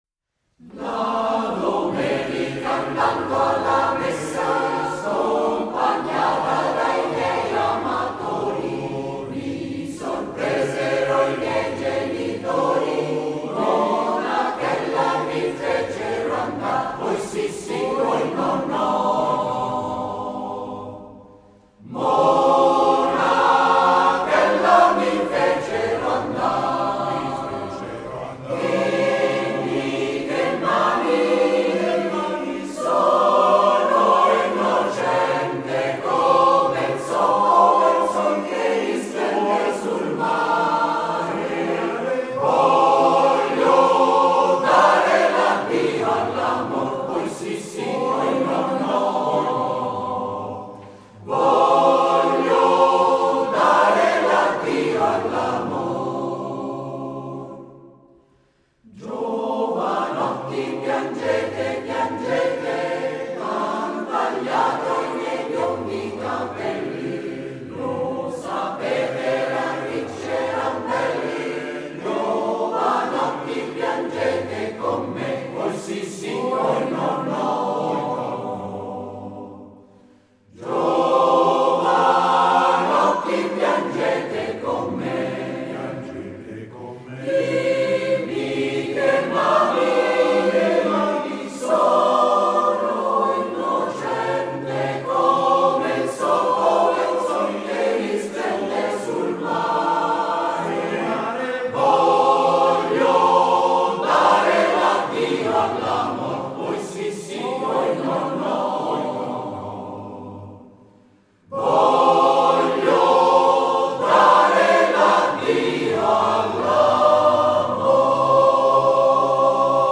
Esecutore: Coro CAI Sezione di Roma